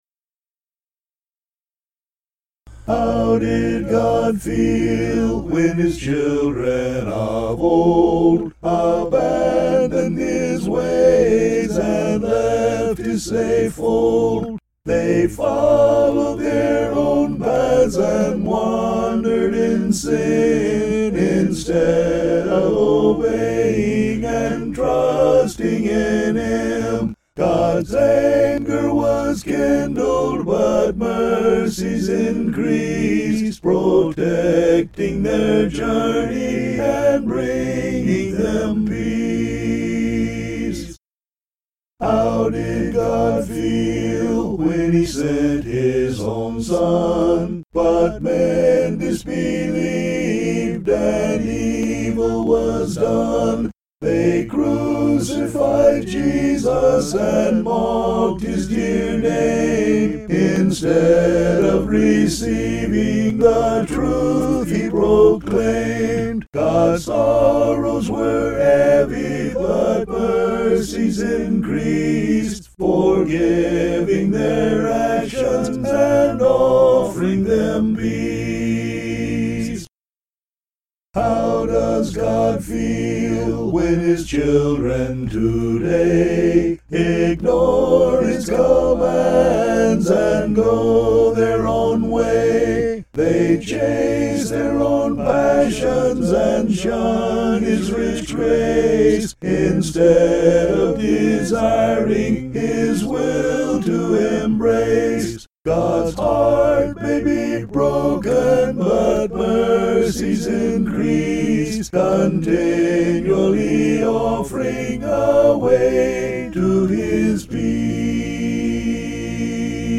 (An original hymn)
Tune: ASPINWALL (adaptation)